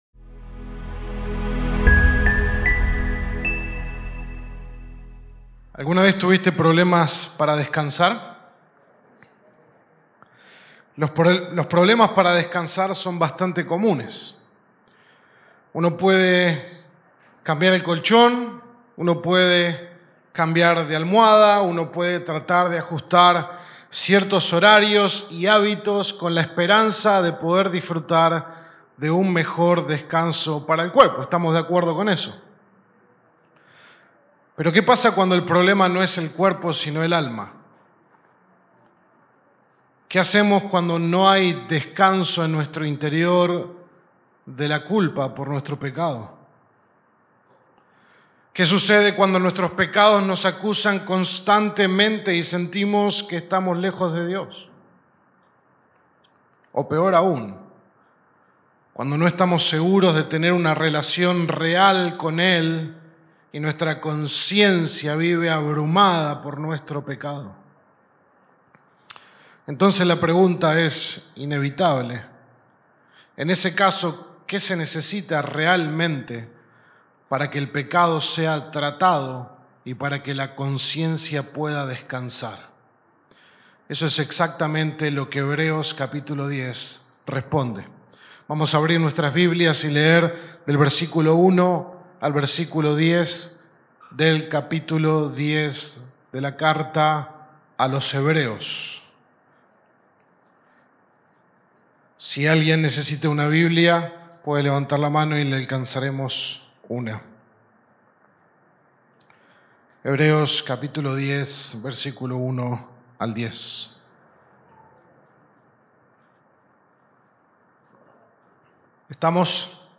Sermón 31 de 33 en Sermones Individuales
Sermon-21-de-Diciembre.mp3